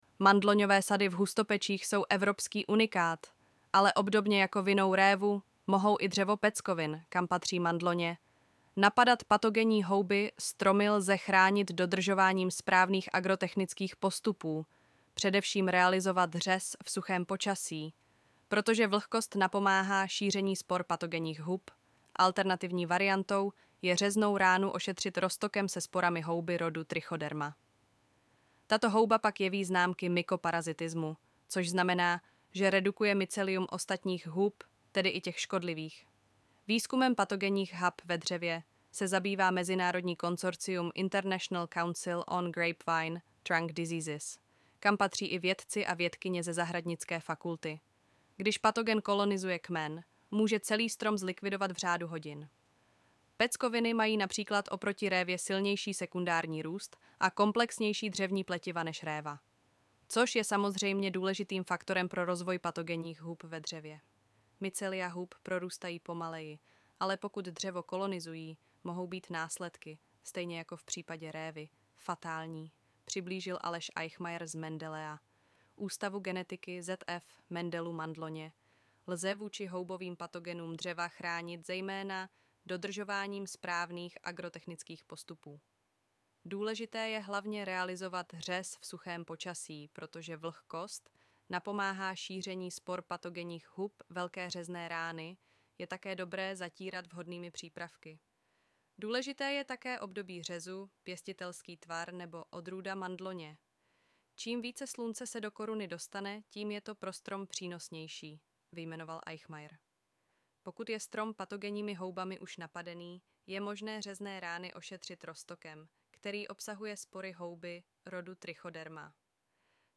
Nechte si zprávu přečíst